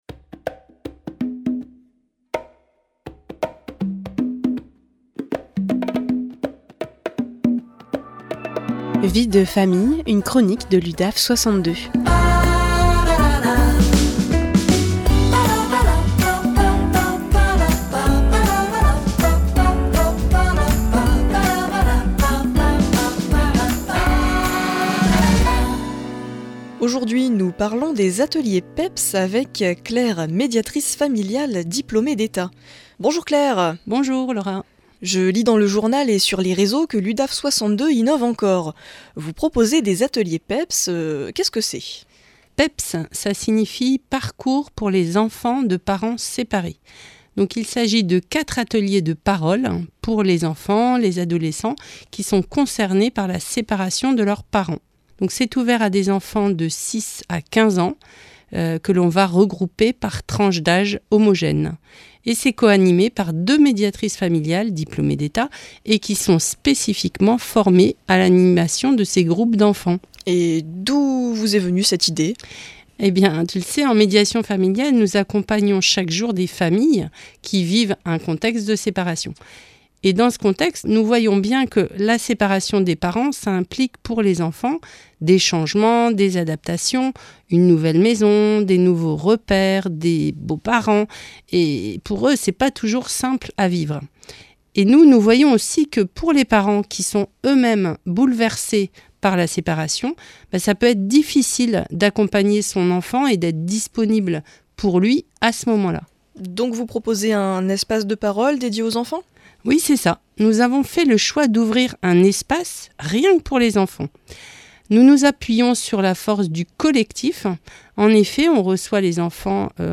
Les professionnels de l’Udaf 62 interviennent au micro de PFM Radio à Arras, en proposant des chroniques sur divers sujets en lien avec leurs services respectifs.
Vie de Famille, une chronique de l’Udaf62 en live sur RADIO PFM 99.9